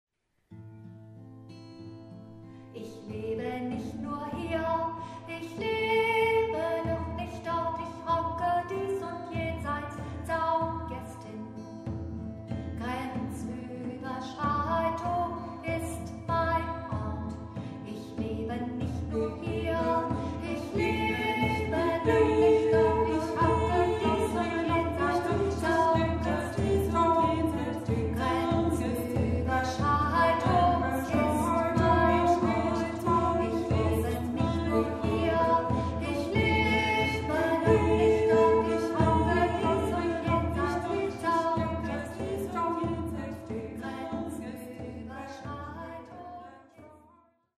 Besetzung: Kanon für zwei Altstimmen, Gitarre, Trommel